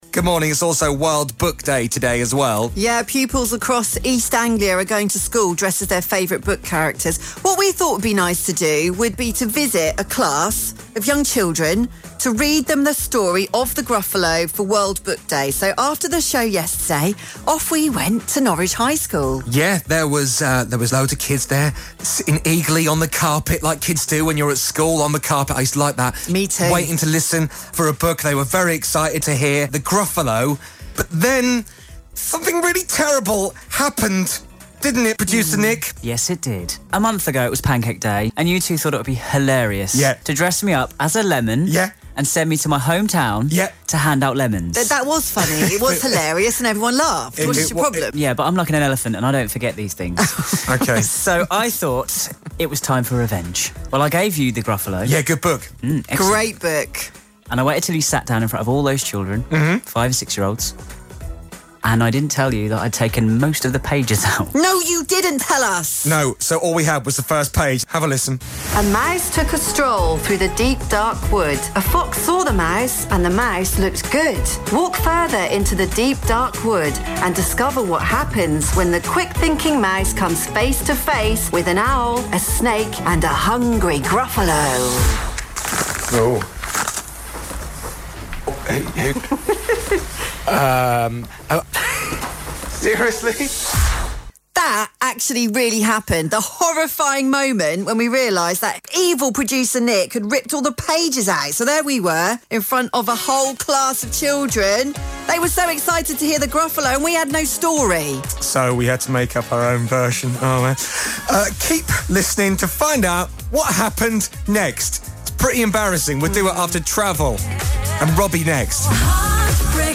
Hear them make it up and read their version of The Gruffly.